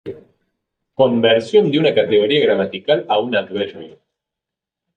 Pronounced as (IPA)
/ɡɾamatiˈkal/